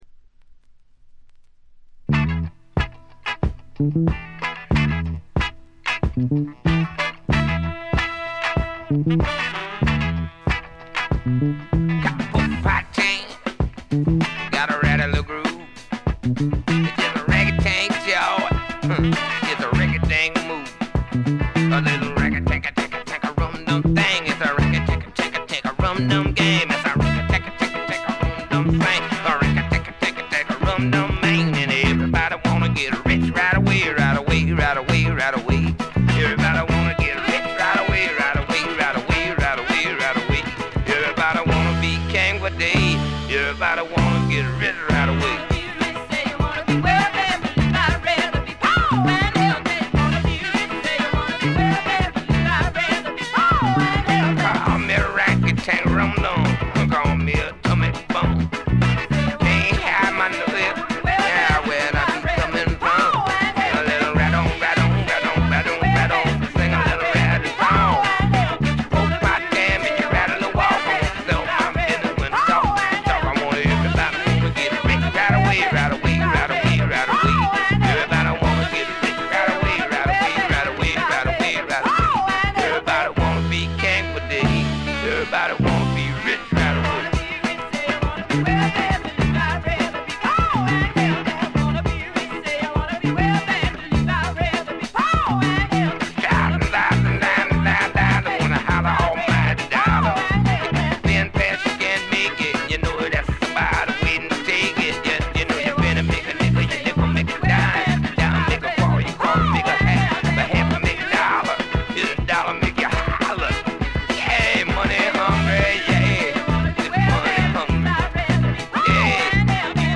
部分試聴ですが、静音部での軽微なチリプチ程度。
試聴曲は現品からの取り込み音源です。